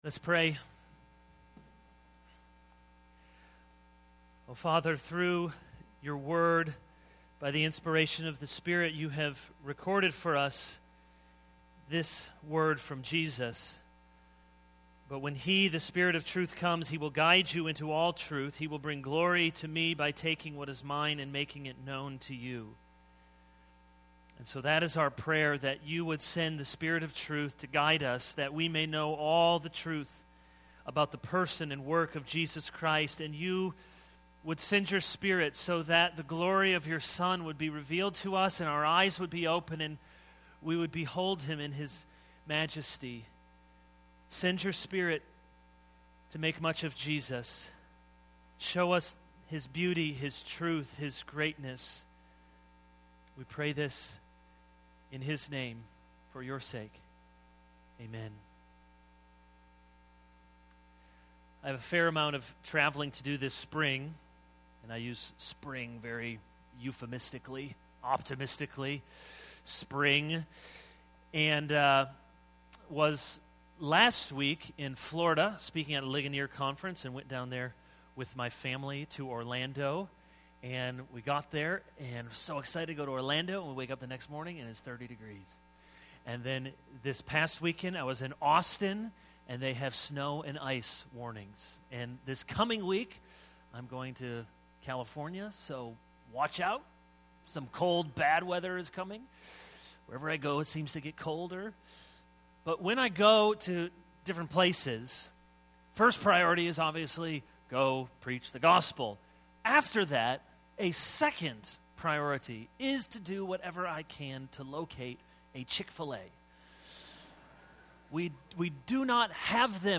This is a sermon on 1 John 2:18-27.